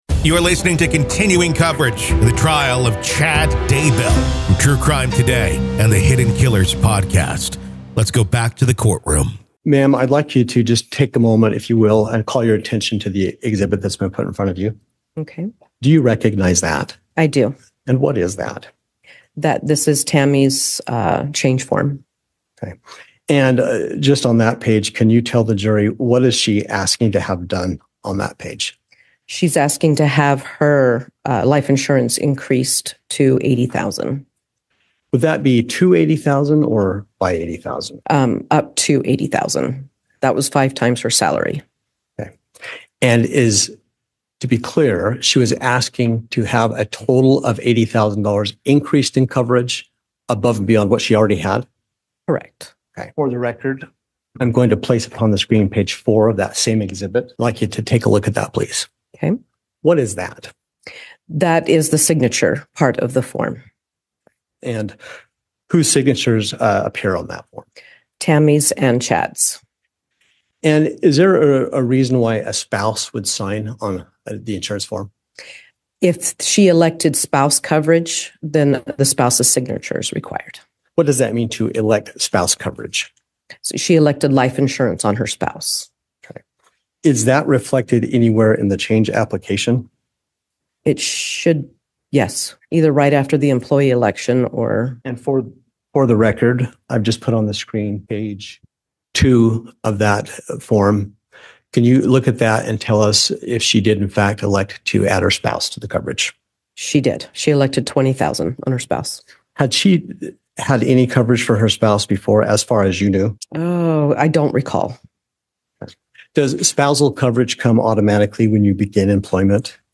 The Trial Of Lori Vallow Daybell | Full Courtroom Coverage / The Trial of Chad Daybell Day 15 Part 2